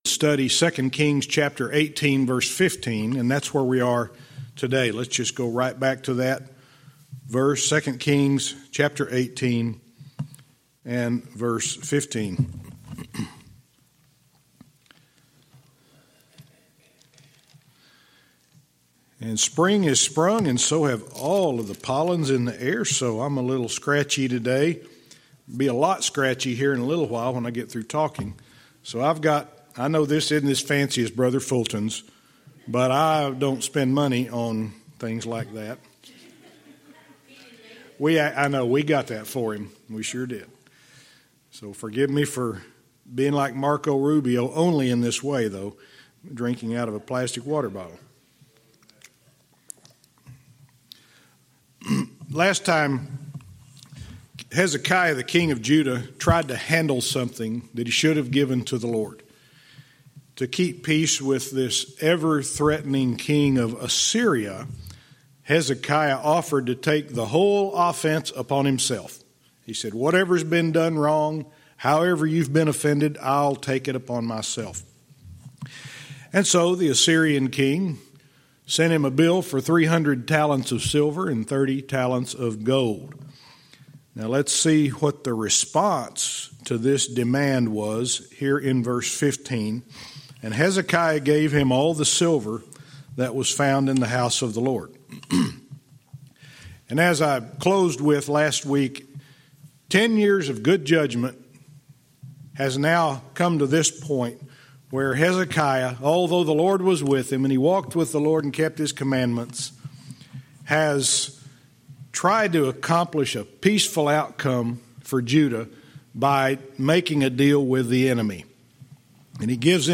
Verse by verse teaching - 2 Kings 18:15(cont)-19